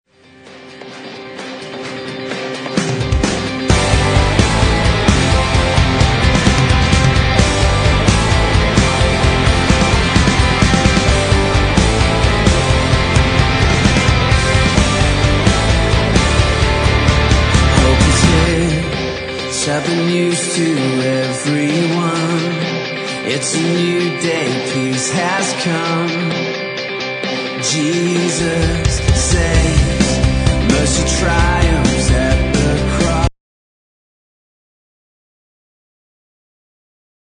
live recording
starting a few measures into an electric guitar riff